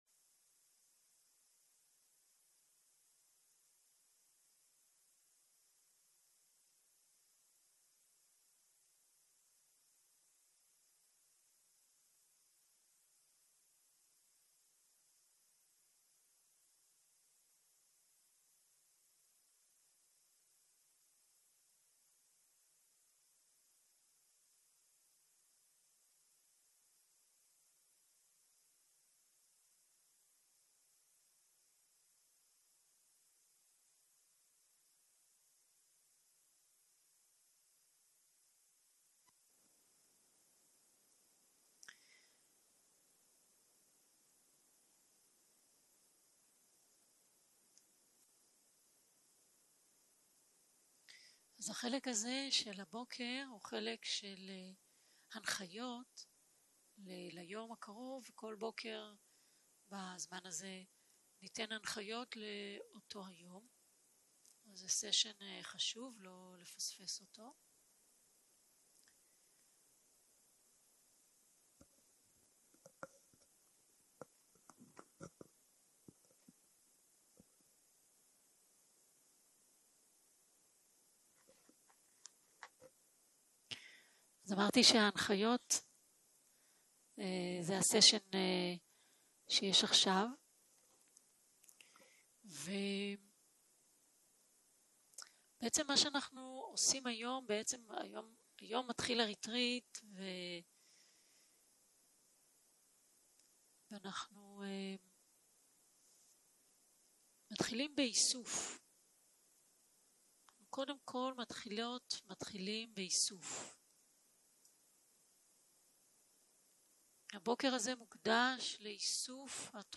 יום 2 - בוקר - הנחיות מדיטציה - הקלטה 2
סוג ההקלטה: שיחת הנחיות למדיטציה